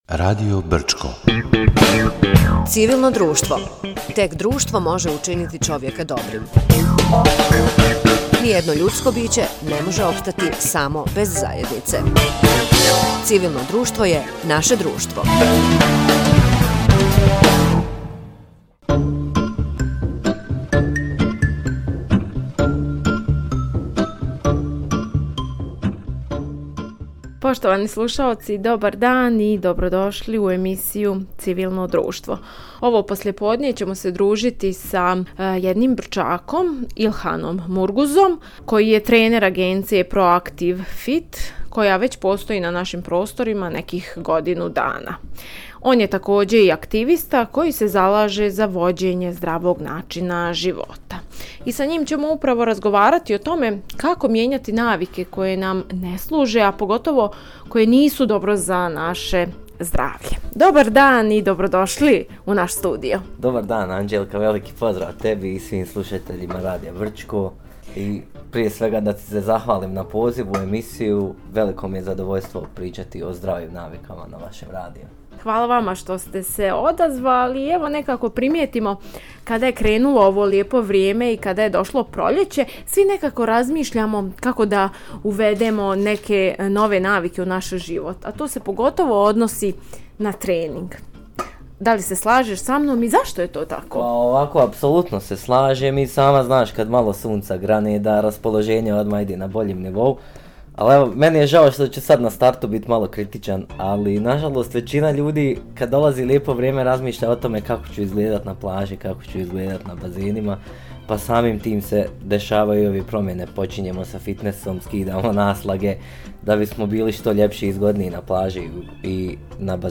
Гост емисије “Цивилно друштво”